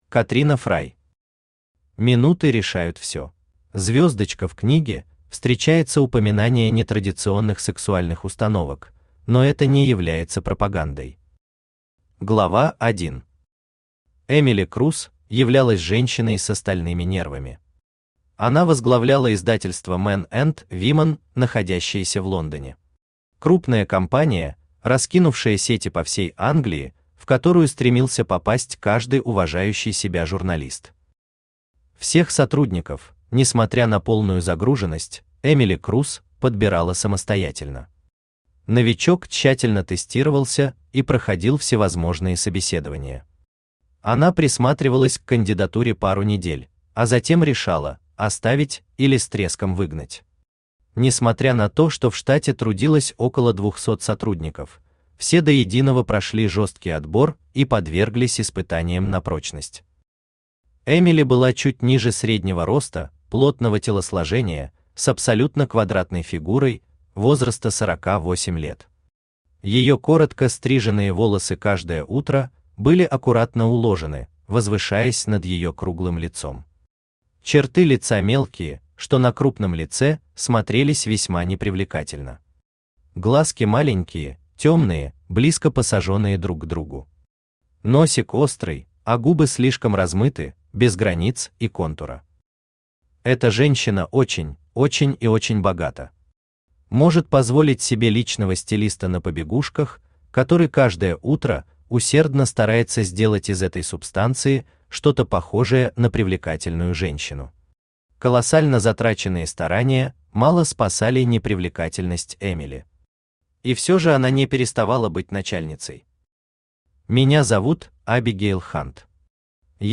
Аудиокнига Минуты решают всё | Библиотека аудиокниг
Aудиокнига Минуты решают всё Автор Катрина Фрай Читает аудиокнигу Авточтец ЛитРес.